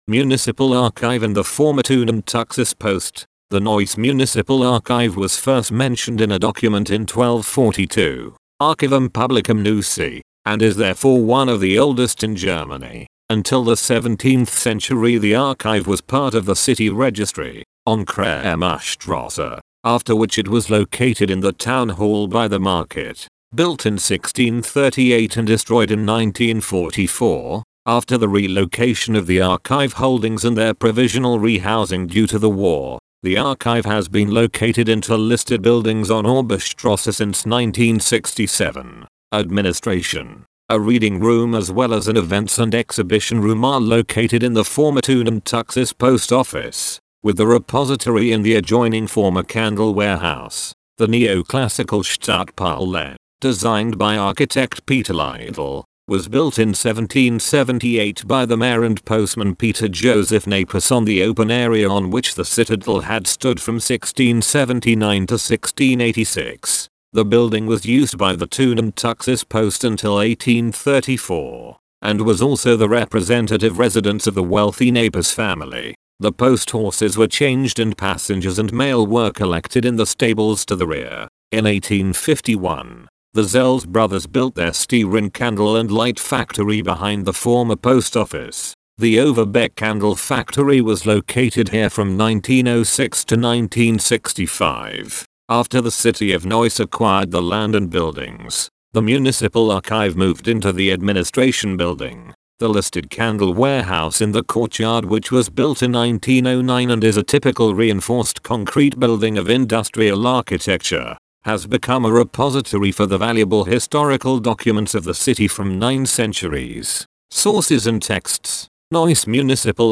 Audio Guide (english)